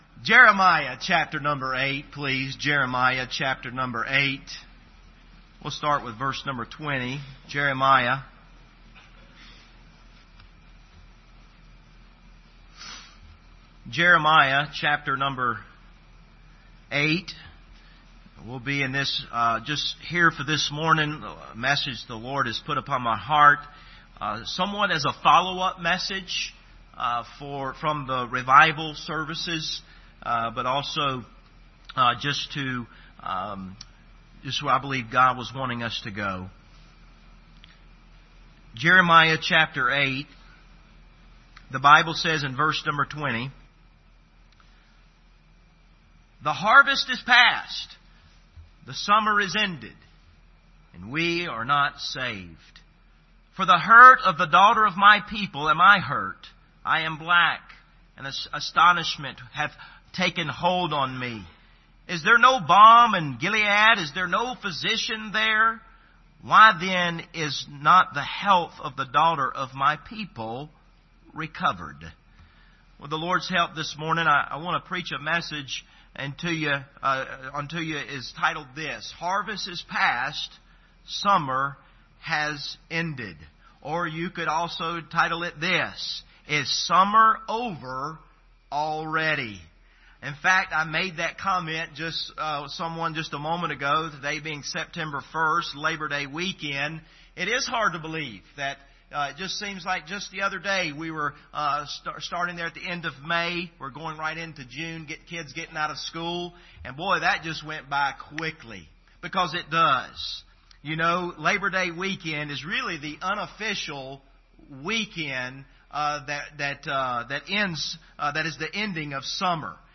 Passage: Jeremiah 8:20 Service Type: Sunday Morning